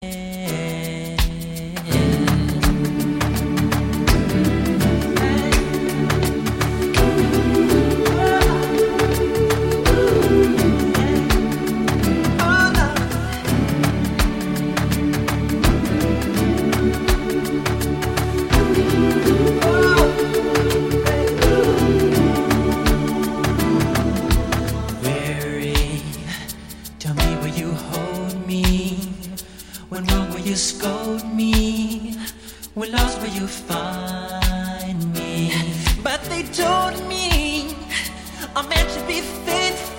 rnb
госпел , поп